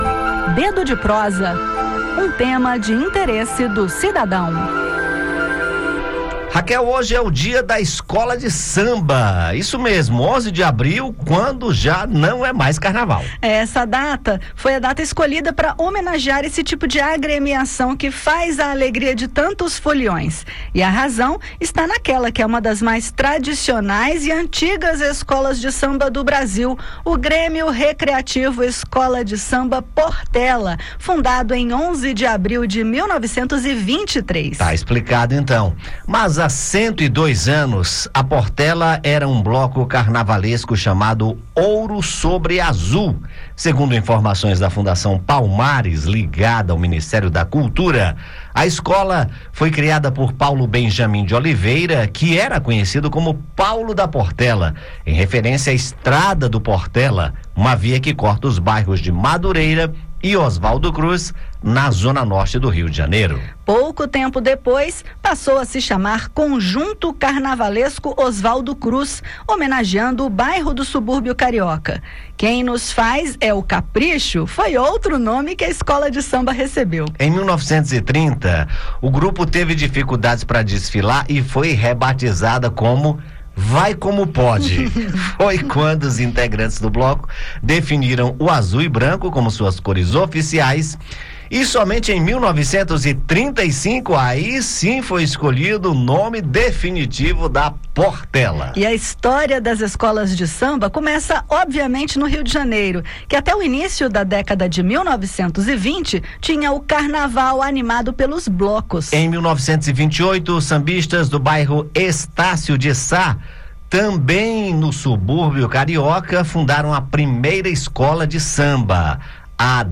O Carnaval já passou, mas é nesta sexta-feira (11) que celebramos oficialmente o Dia das Escolas de Samba — uma data que homenageia a fundação da Portela, em 1926. Ouça o bate-papo e mergulhe na rica história de um dos pilares da nossa cultura popular.